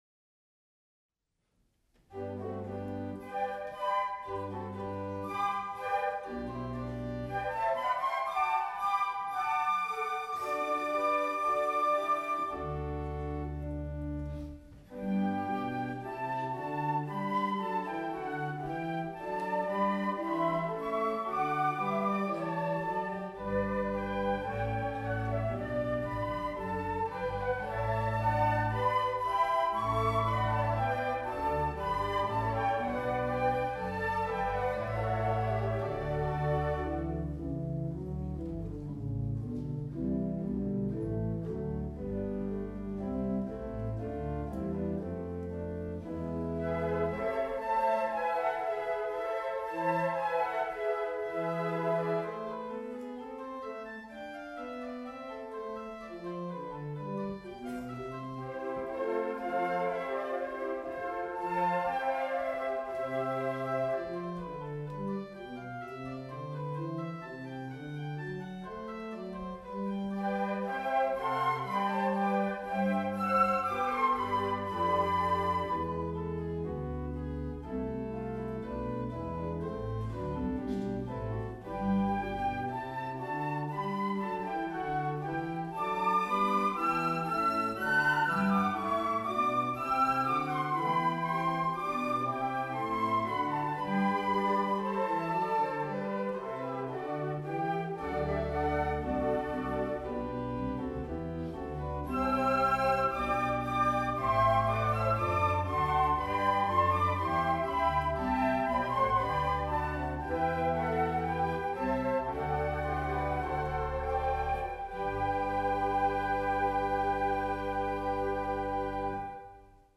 Querflöten Konzert
Orgel Leeste
Rund vierzig(!) Ausführende waren mit ihrer Querflöte
Ausschnitte aus dem Konzert: